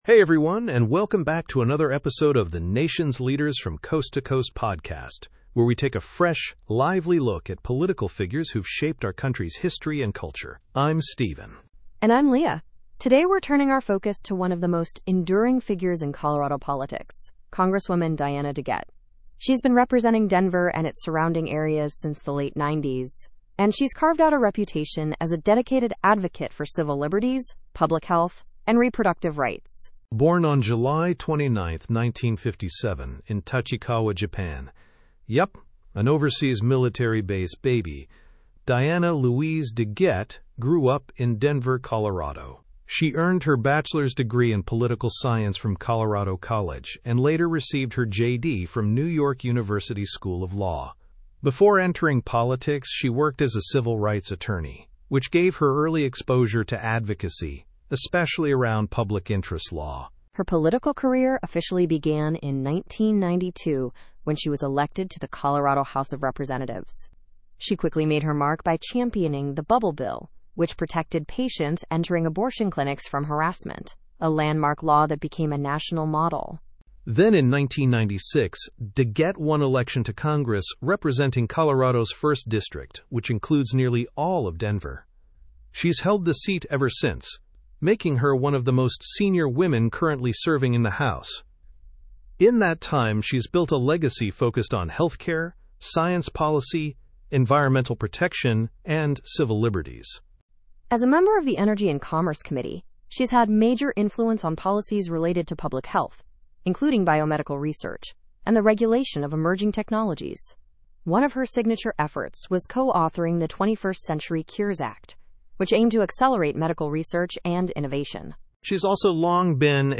Whether you’re a Coloradan, a public health advocate, or simply curious about one of Congress’s most consistent progressive voices, this episode offers an insightful, conversational profile of a leader who’s shaped over two decades of national policy.